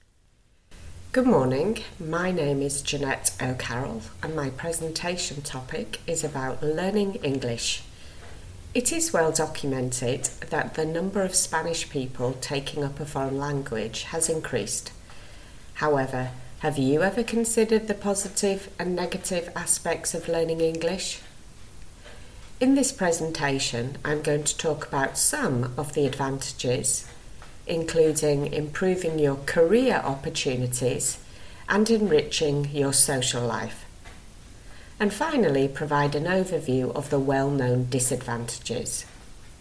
• Exam-ready recorded monologue (MP3)
b2-monologue-learning-english-sneak-peek.mp3